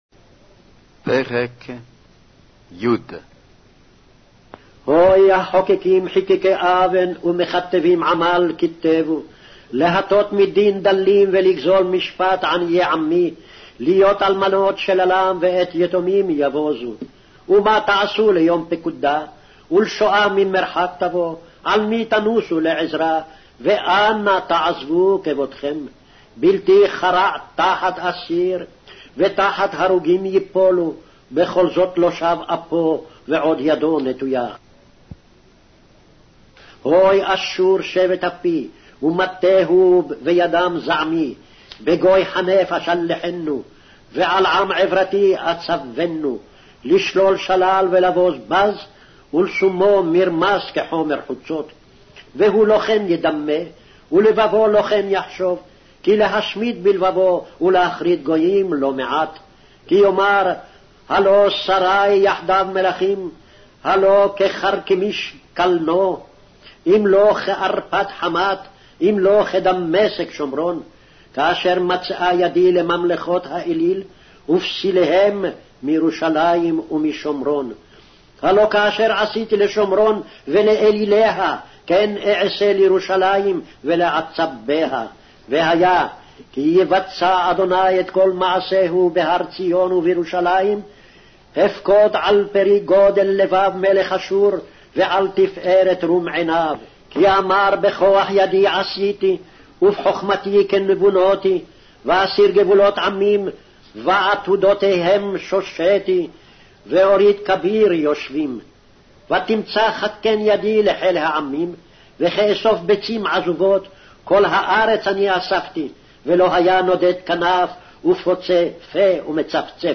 Hebrew Audio Bible - Isaiah 57 in Gnterp bible version